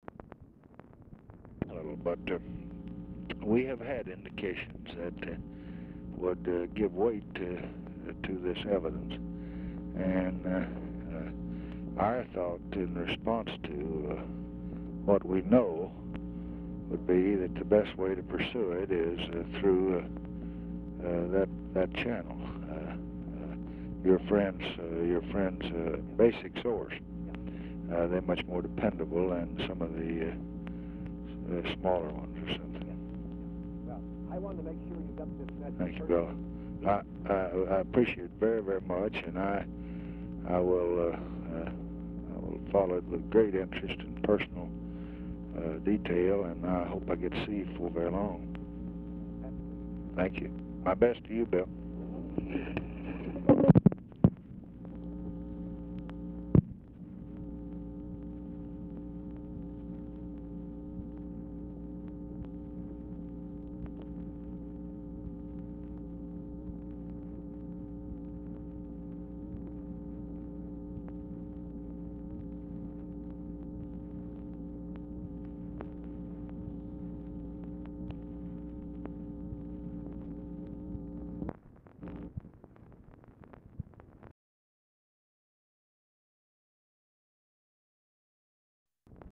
Telephone conversation # 11309, sound recording, LBJ and WILLIAM O. DOUGLAS, 1/4/1967, 6:52PM | Discover LBJ
DOUGLAS IS DIFFICULT TO HEAR
Format Dictation belt
Location Of Speaker 1 Oval Office or unknown location